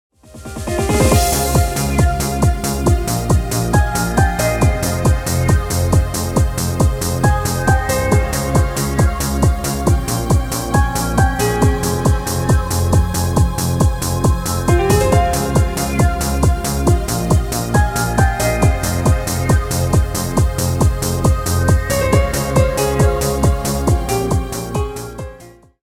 Поп Музыка # без слов